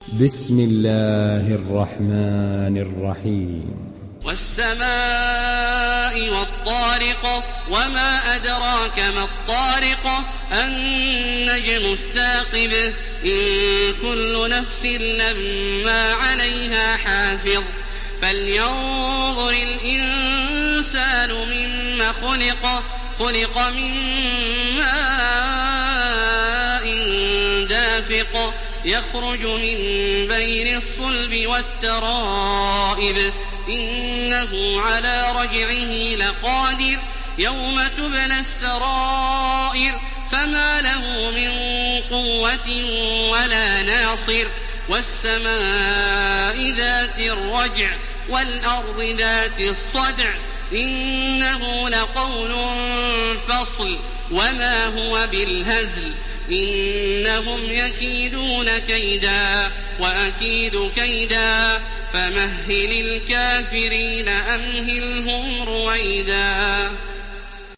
Télécharger Sourate At Tariq Taraweeh Makkah 1430